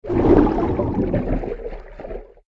AV_swim_single_stroke.ogg